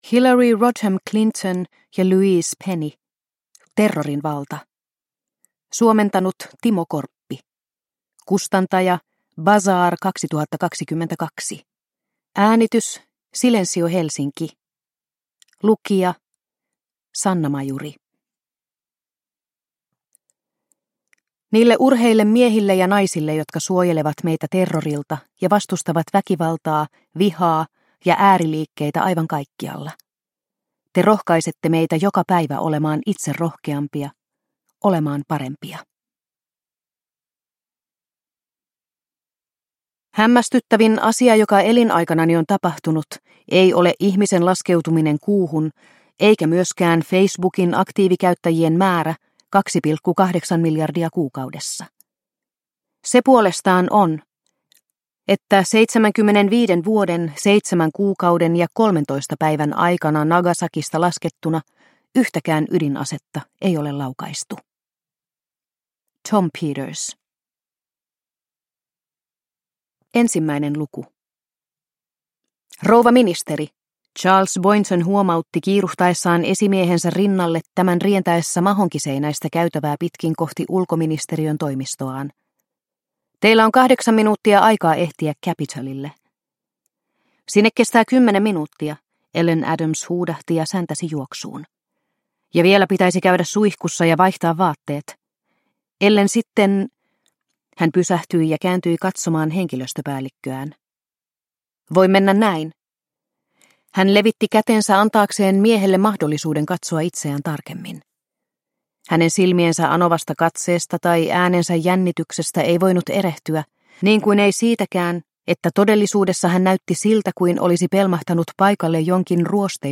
Terrorin valta – Ljudbok – Laddas ner